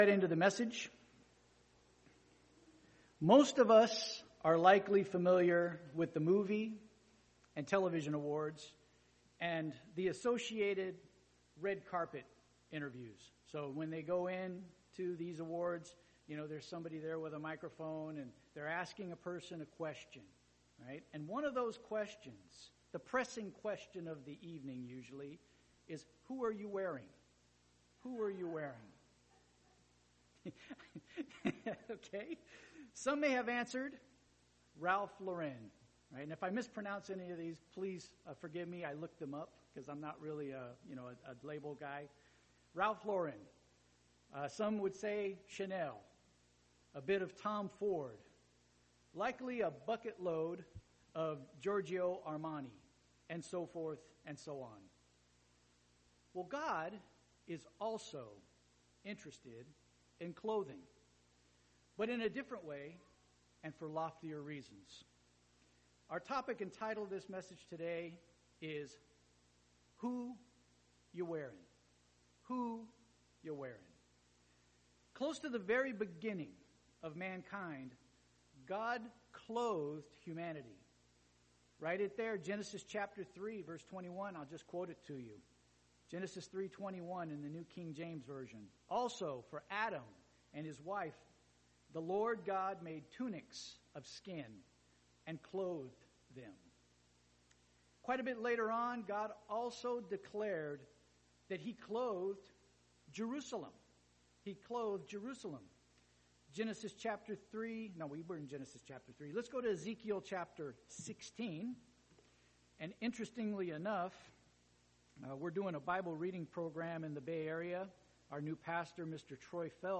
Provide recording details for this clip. Given in Eureka, CA